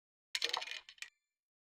sprayer_take_oneshot_004.wav